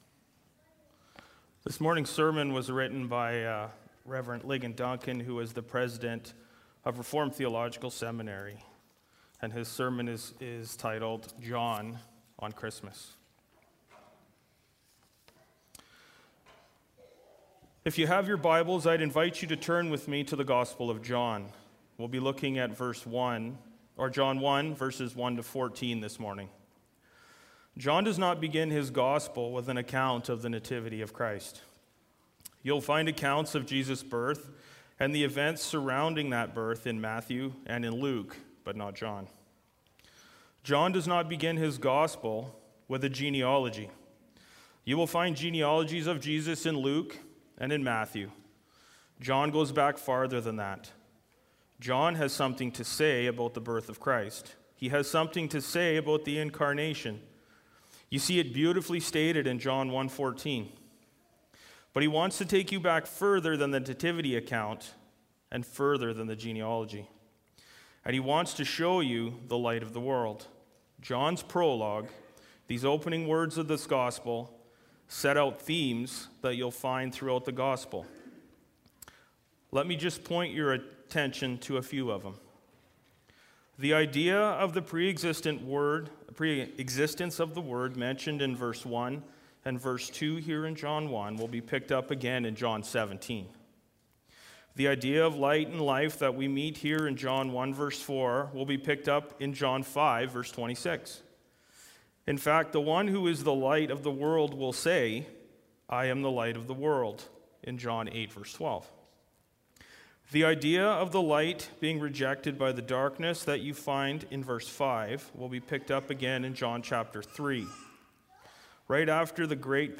Sermons | Ebenezer Christian Reformed Church
Guest Speaker